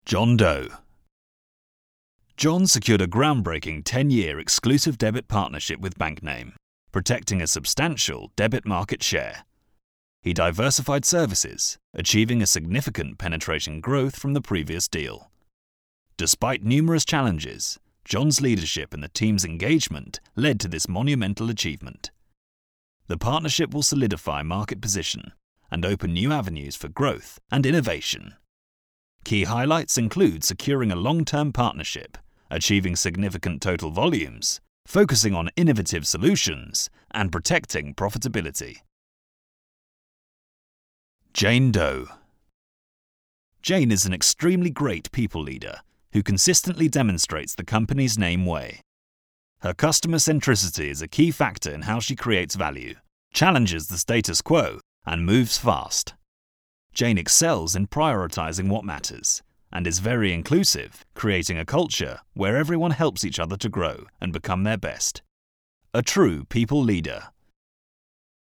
Anuncios
Mi voz cálida y tranquilizadora puede darle vida a cualquier guion, proyecto o resumen.